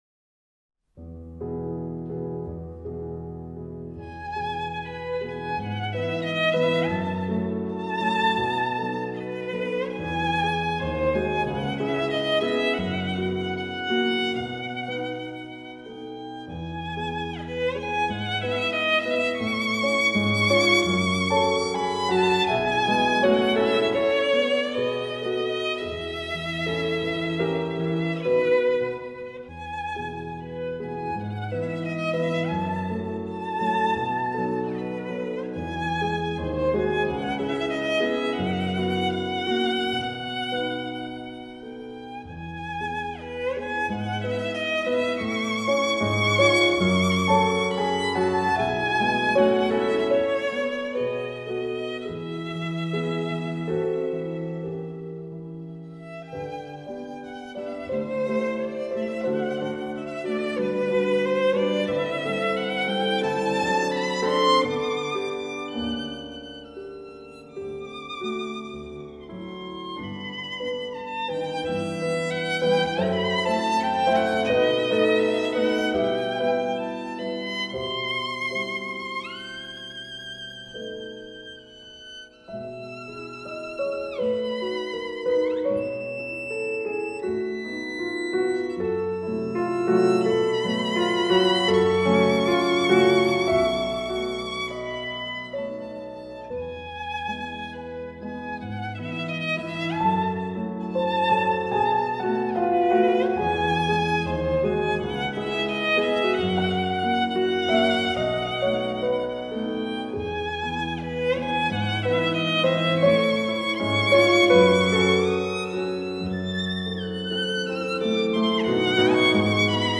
موسیقی کلاسیک شاهکار به نام " Salut d'amour, Op. 12 " از آهنگساز بریتانیایی " Edward Elgar "